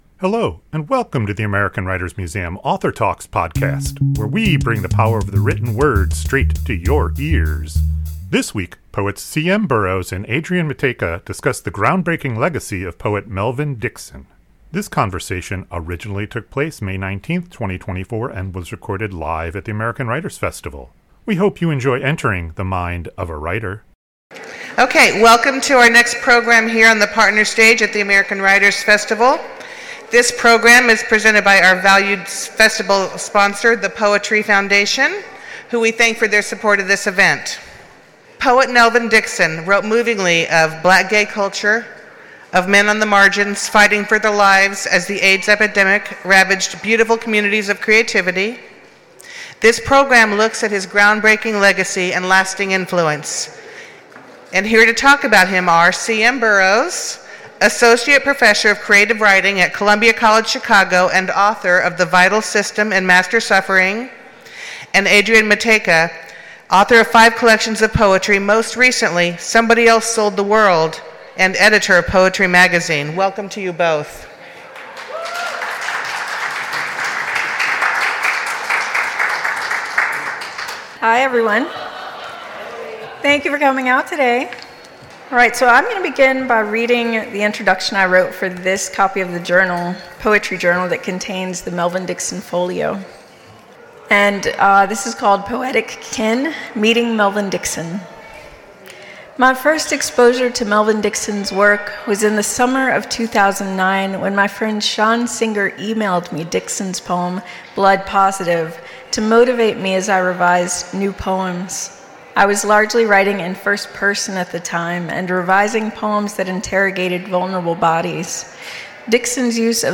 Presented by the Poetry Foundation. This conversation originally took place May 19, 2024 and was recorded live at the American Writers Festival.